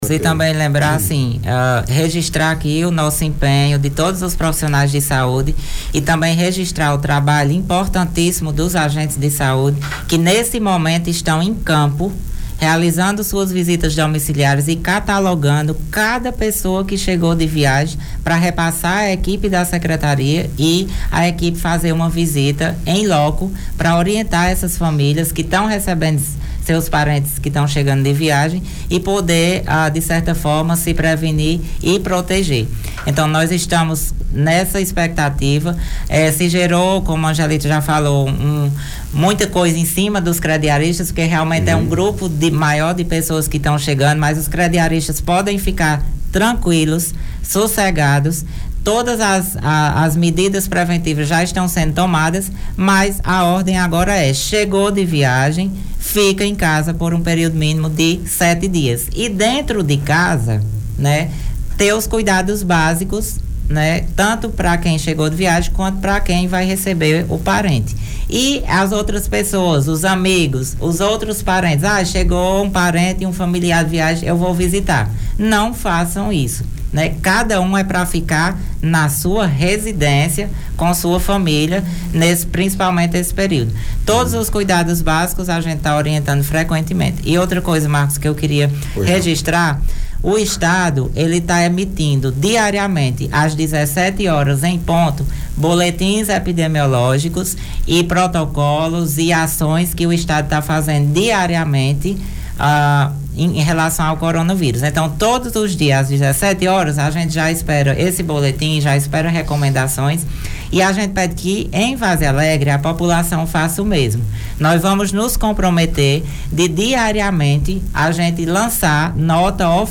Em entrevista à FM Cultura 96.3, o Secretário de Saúde Ivo Leal, recomendou que as pessoas que chegarem de viagem fiquem em casa por um certo período.